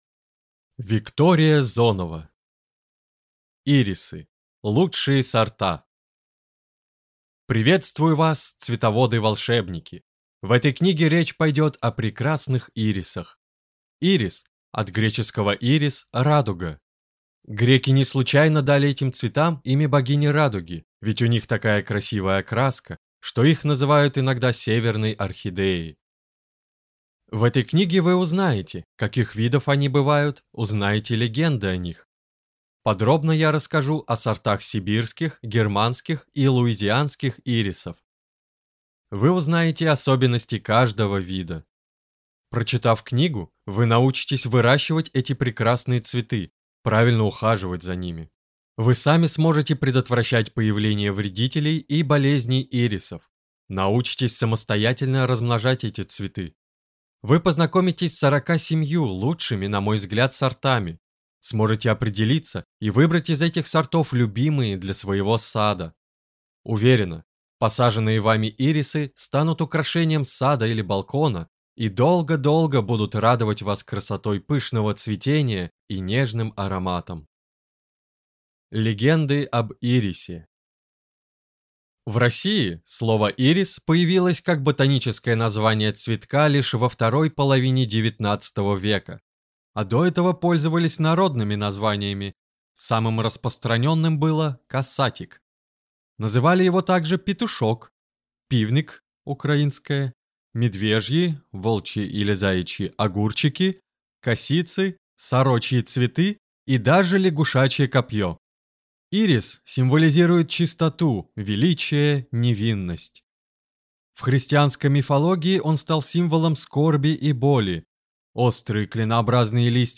Аудиокнига Ирисы. Лучшие сорта | Библиотека аудиокниг